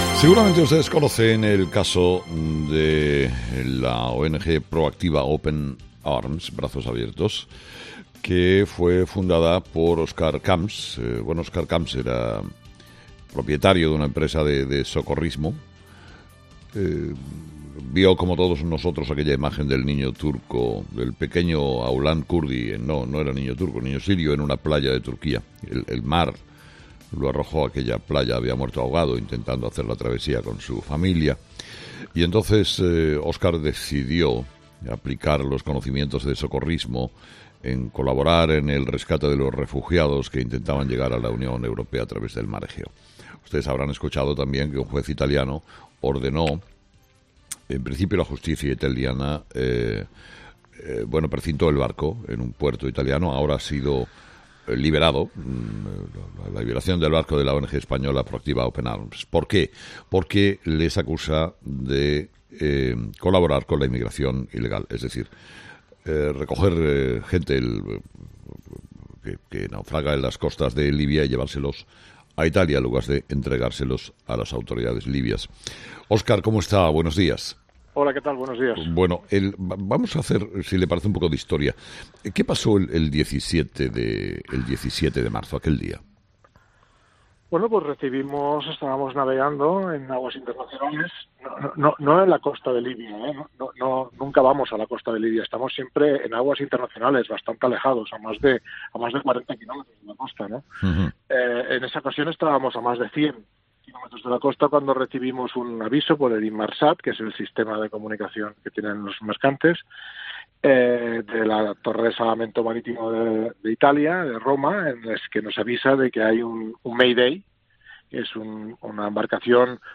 Entrevista a Óscar Camps, el fundador de la ONG Pro Activa Open Arms